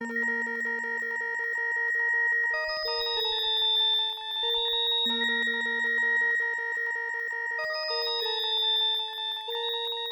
描述：诡异的
Tag: 95 bpm Weird Loops Fx Loops 1.70 MB wav Key : Unknown